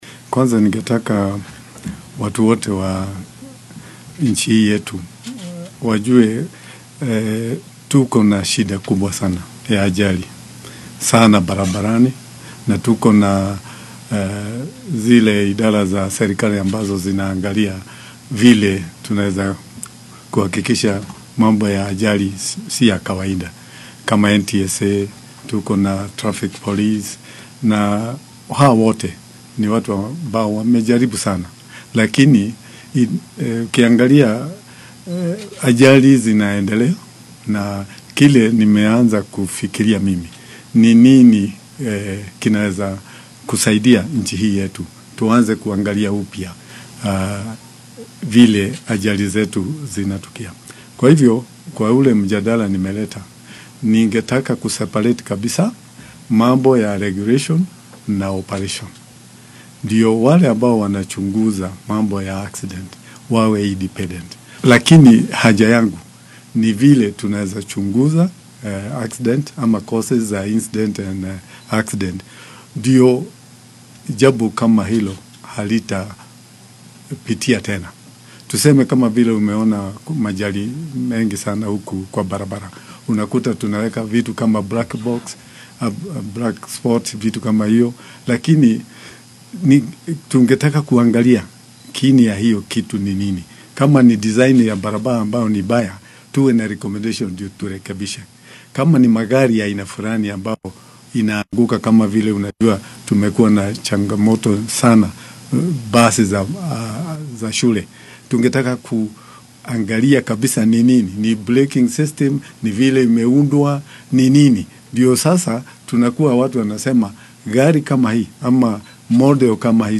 Mooshinkiisa ayaa sidoo kale ku saabsan shilalka diyaaradaha , doomaha ku qallibma badaha iyo dhimashooyinka ka dhasha gaaska cuntada lagu karsado. Xilli uu arrimahan faahfaahin ka bixinayay ayuu yidhi.